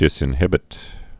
(dĭsĭn-hĭbĭt)